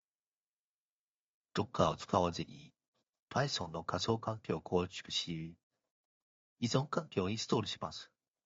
男声を選択します。